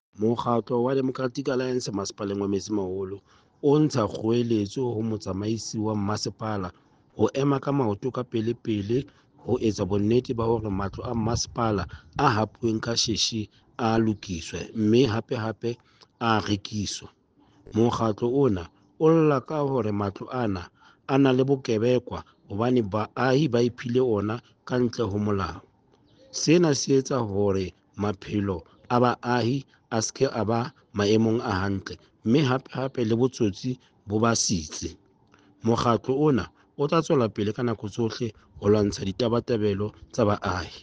Sesotho soundbite by Cllr Stone Makhema.